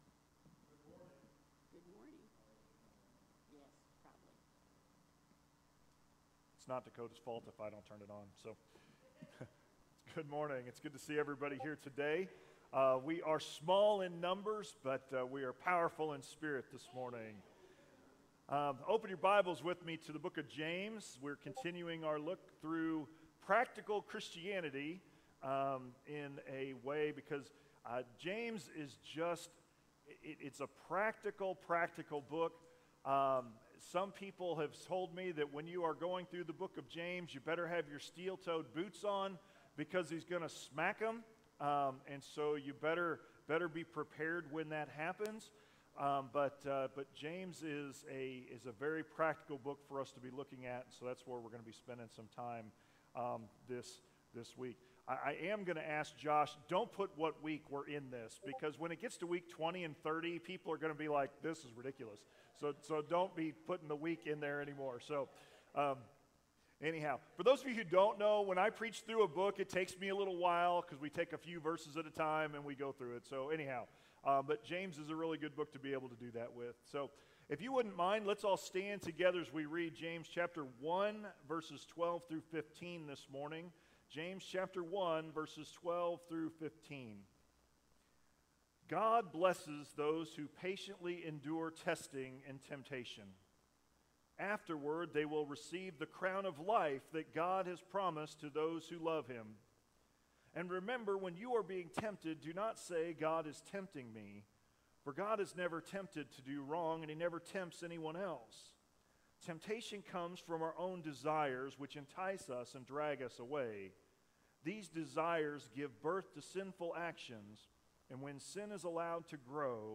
Sermons | Centennial Baptist Church